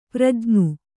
♪ prajñu